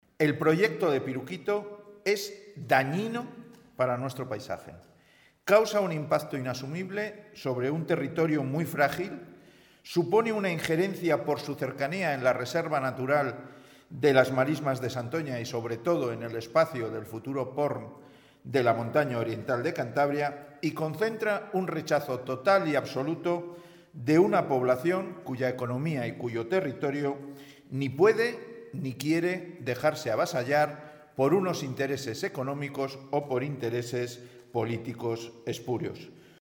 Ver declaraciones de Pedro Hernando, portavoz del Grupo Parlamentario Regionalista.